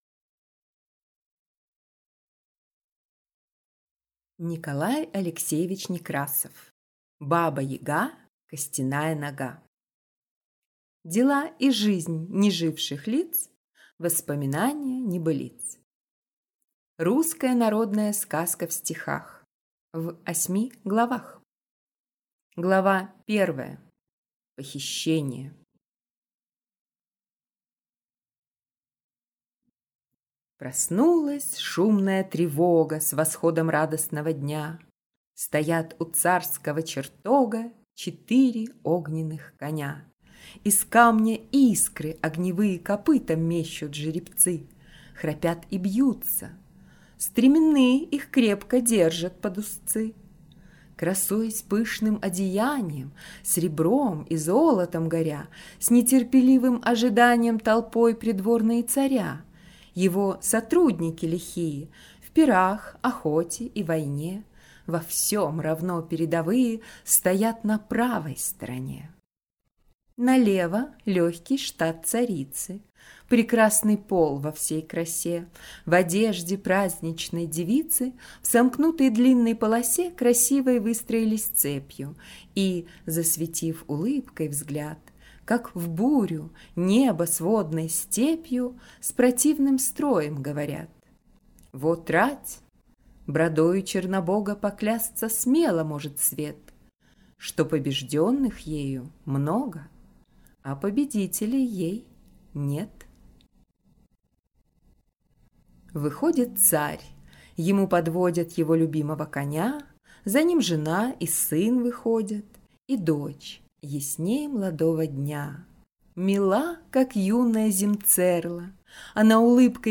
Аудиокнига Баба-Яга, Костяная Нога. Русская народная сказка в стихах.